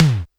80s Digital Tom 04.wav